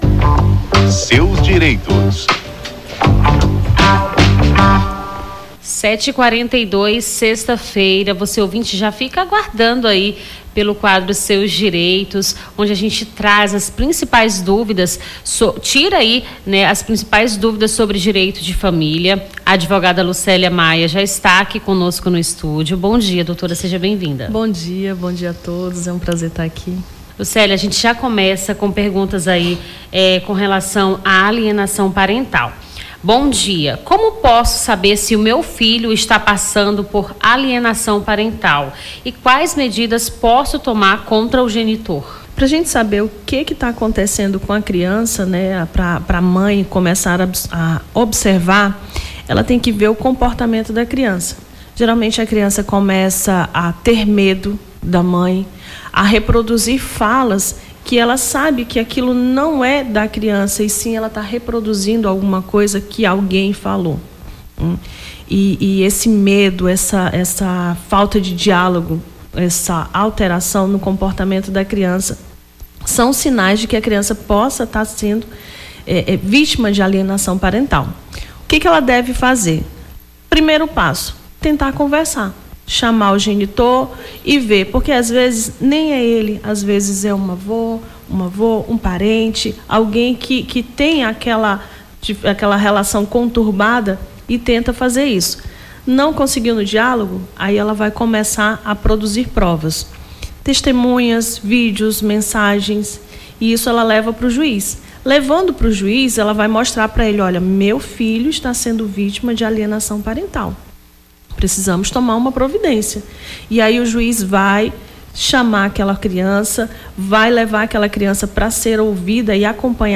Seus Direitos: advogada esclarece dúvidas dos ouvintes sobre direito de família